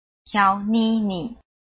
臺灣客語拼音學習網-客語聽讀拼-詔安腔-單韻母
拼音查詢：【詔安腔】ni ~請點選不同聲調拼音聽聽看!(例字漢字部分屬參考性質)